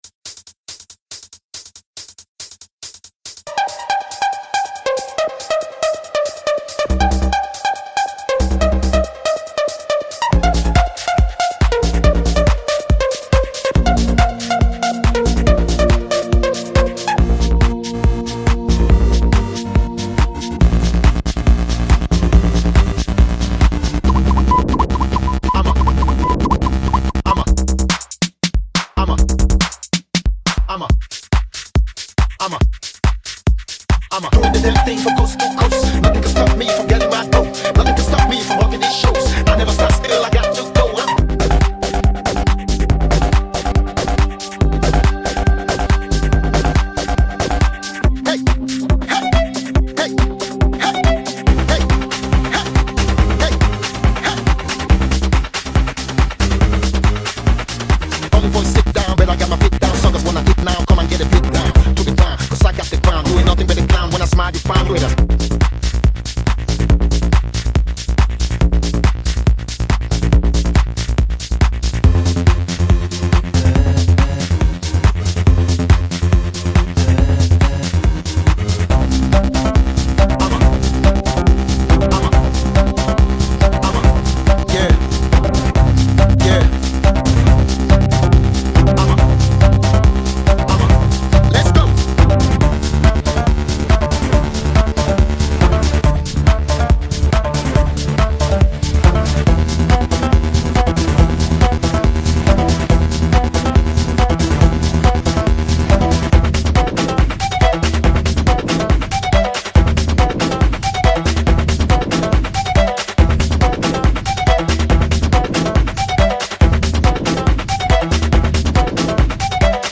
GENERE: POP -  ROCK - ACUSTICO